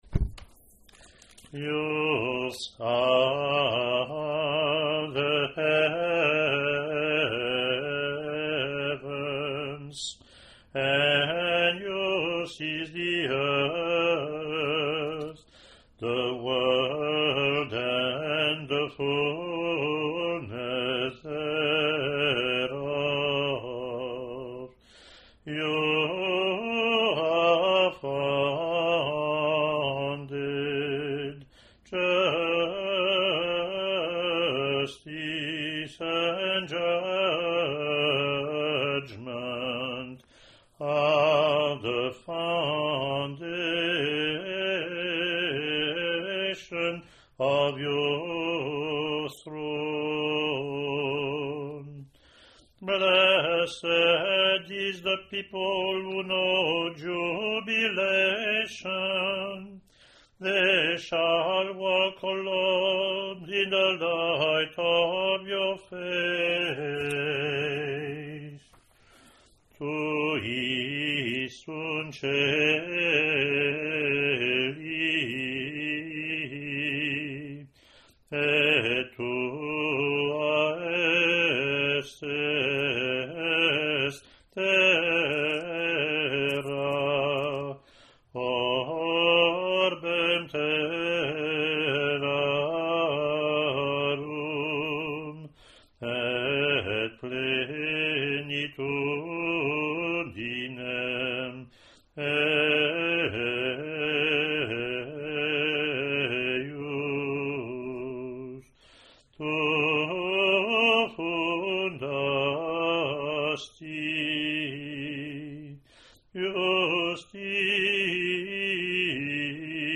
Offertory (English antiphon – English verse – Latin antiphon)